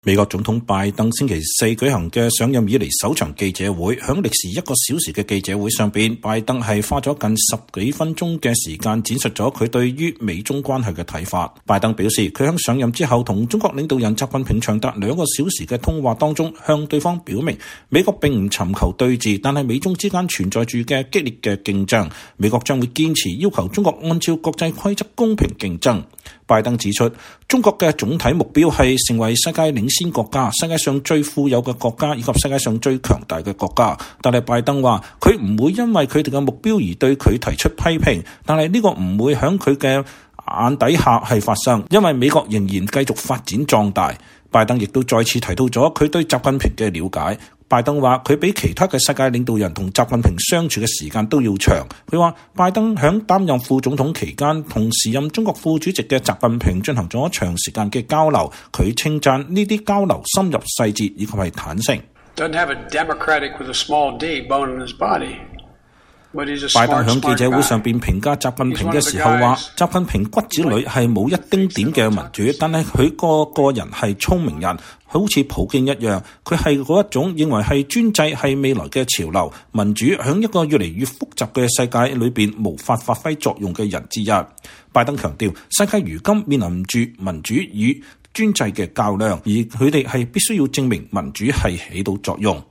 美國總統拜登星期四（3月25日）舉行了上任以來的首場記者會。在歷時一個小時的記者會上，拜登花了近十分鐘的時間闡述了他對於美中關係的看法。